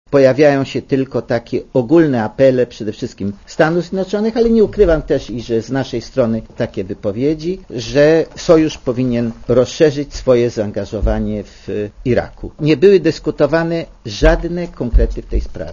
Komentarz audio (61kB)